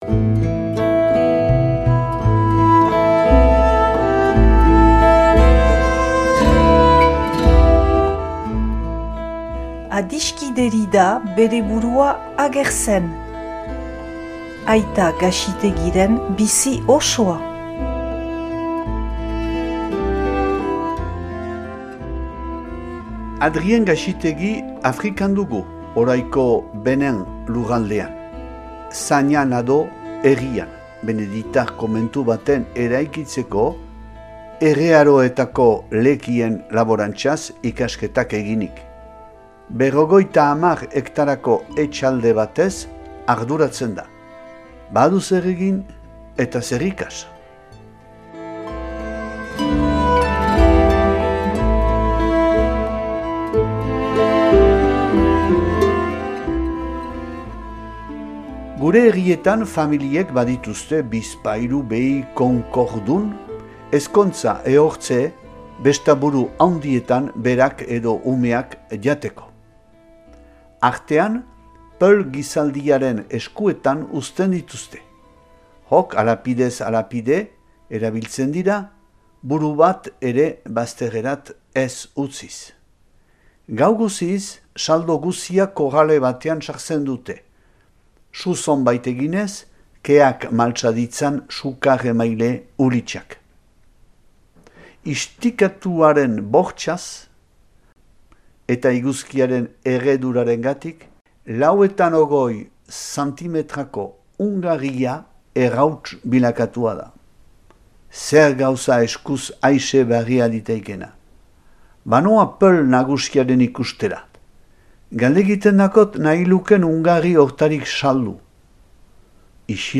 irakurketa bat dauzuegu eskaintzen